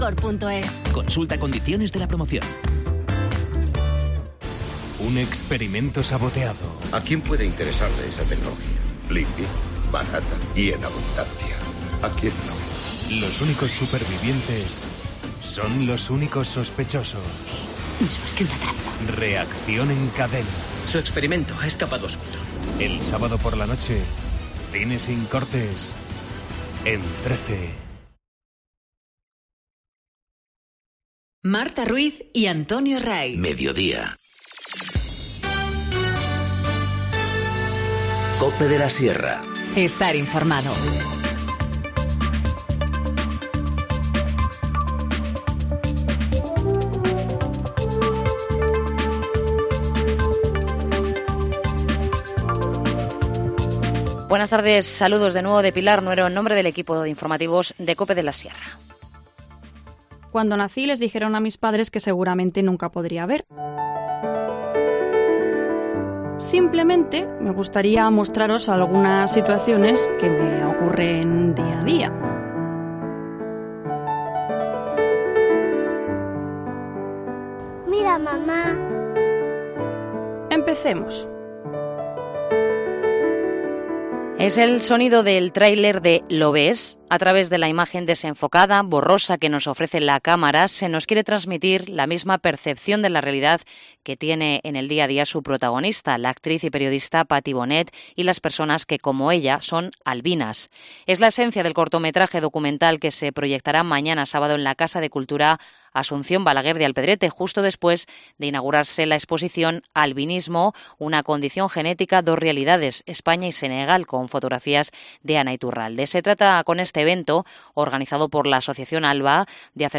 Informativo Mediodía 30 nov- 14:50h